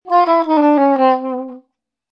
Descarga de Sonidos mp3 Gratis: saxofon 23.